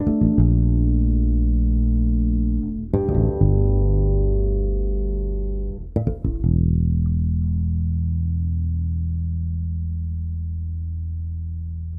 低音融合4
描述：适用于许多流派的无品类爵士贝斯的旋律
Tag: 80 bpm Fusion Loops Bass Guitar Loops 2.02 MB wav Key : E